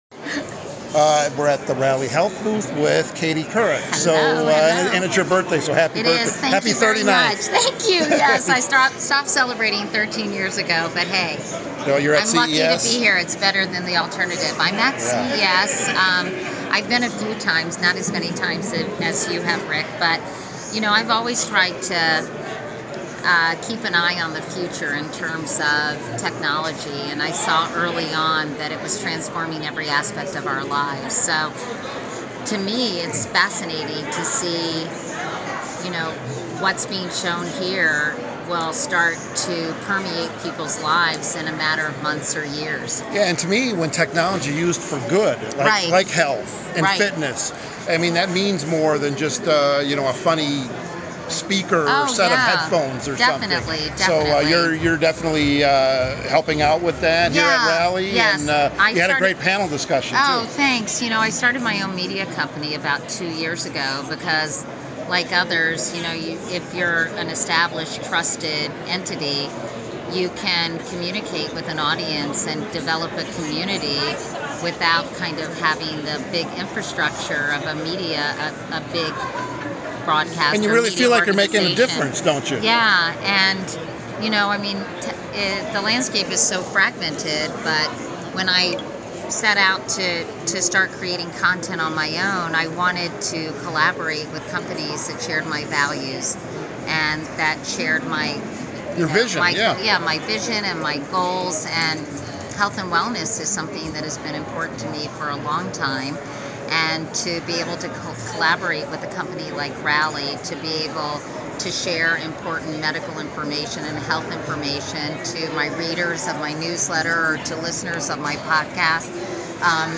at the Rally Health area at CES 2020